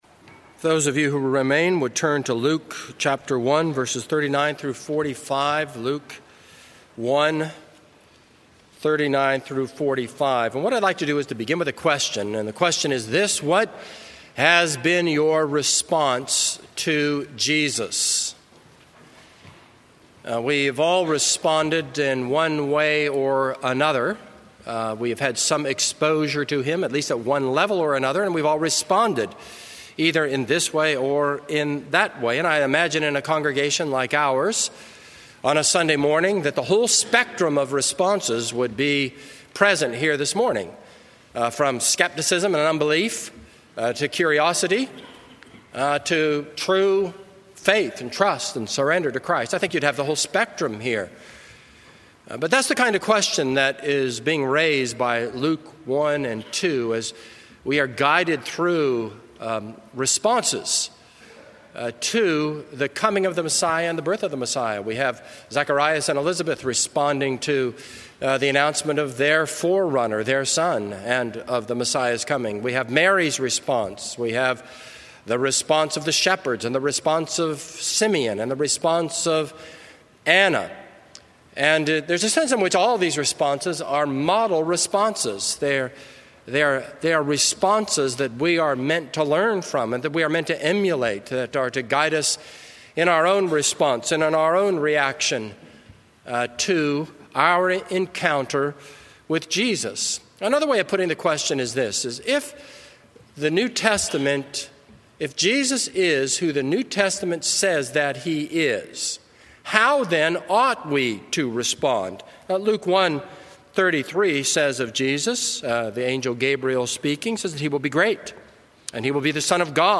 This is a sermon on Luke 1:39-45.